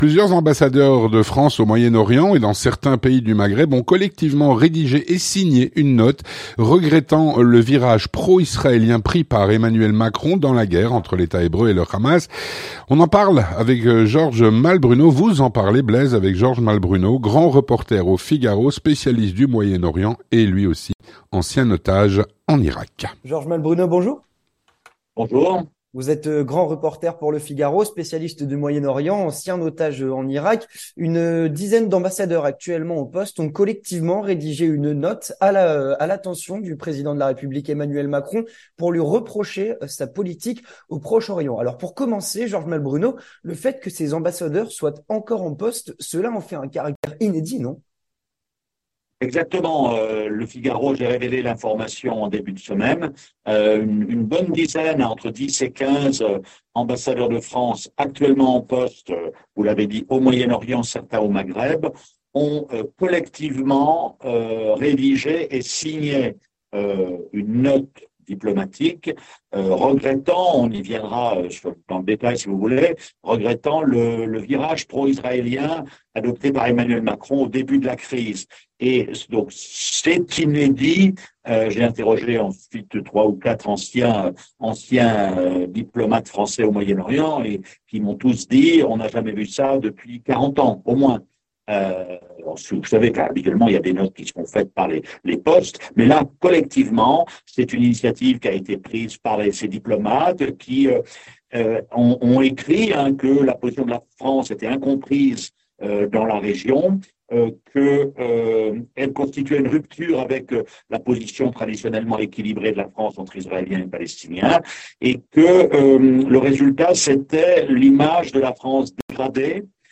L'entretien du 18H - Plusieurs ambassadeurs de pays arabes se plaignent de la position "pro-israélienne" que semble adopter, selon eux, Emmanuel Macron.
Avec Georges Malbrunot, grand reporter au Figaro, spécialiste du Moyen Orient et ancien otage en Irak.